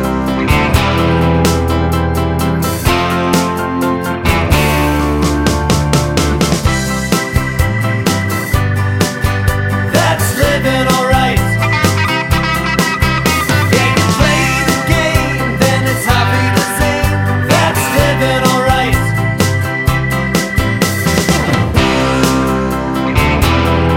T.V. Themes